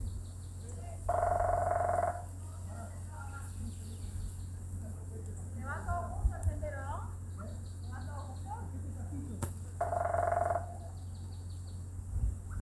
Pale-crested Woodpecker (Celeus lugubris)
Location or protected area: Parque Nacional El Impenetrable
Condition: Wild
Certainty: Photographed, Recorded vocal